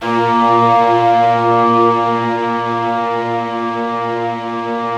BIGORK.A#1-R.wav